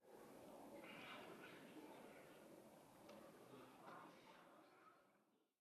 Minecraft Version Minecraft Version snapshot Latest Release | Latest Snapshot snapshot / assets / minecraft / sounds / ambient / nether / soulsand_valley / whisper7.ogg Compare With Compare With Latest Release | Latest Snapshot
whisper7.ogg